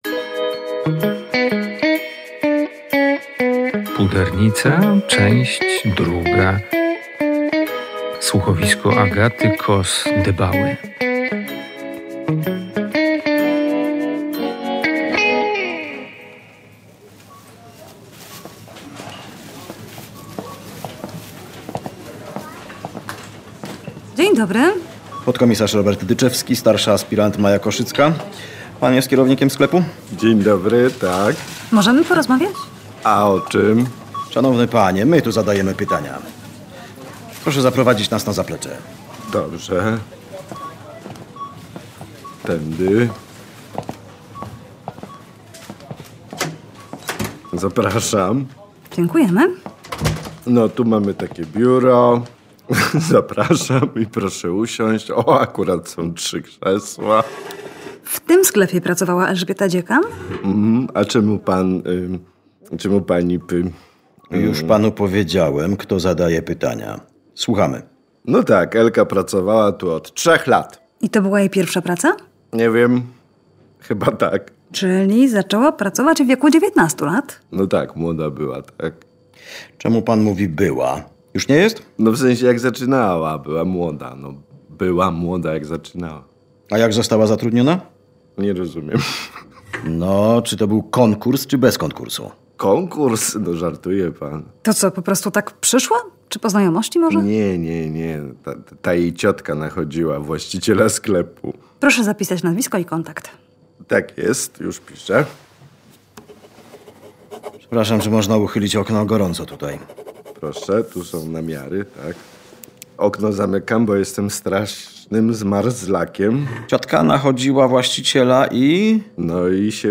Przed nami druga część słuchowiska „Pudernica”.